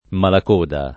vai all'elenco alfabetico delle voci ingrandisci il carattere 100% rimpicciolisci il carattere stampa invia tramite posta elettronica codividi su Facebook Malacoda [ malak 1 da ] pers. m. — uno dei diavoli dell’Inferno dantesco